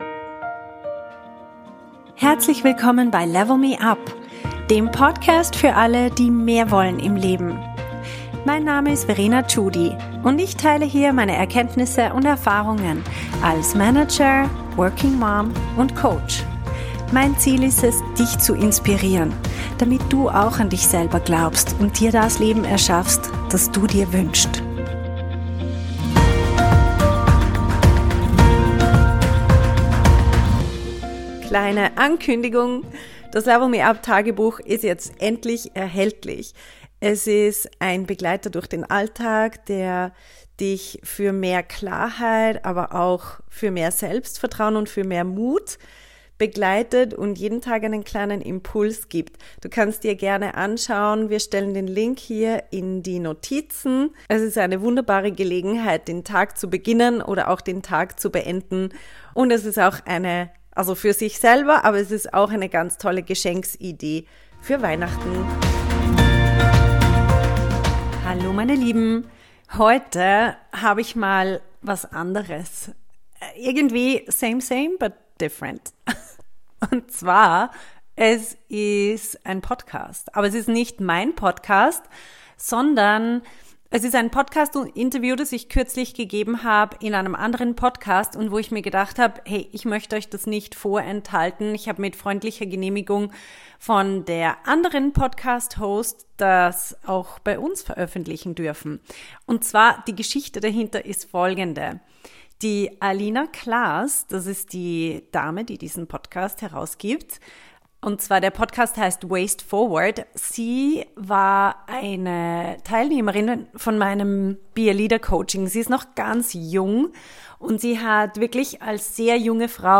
Zwischen Machtspielen und Leadership – Interview